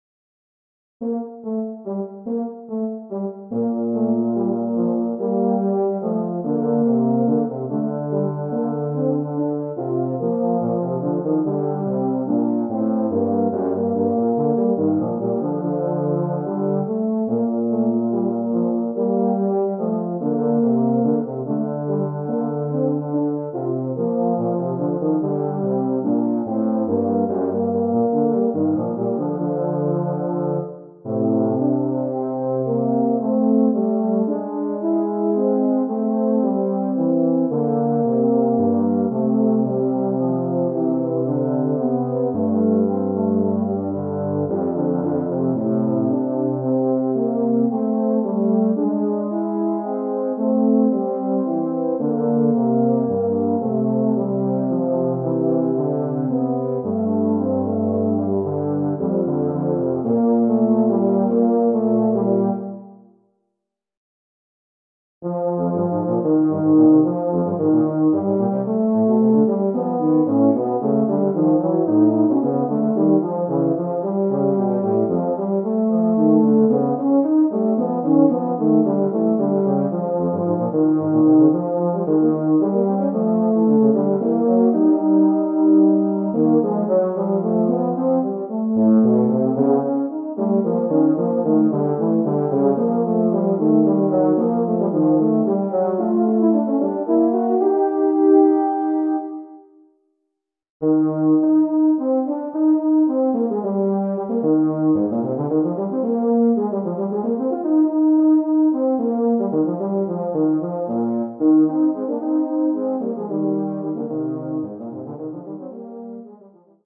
Voicing: Euphonium Duet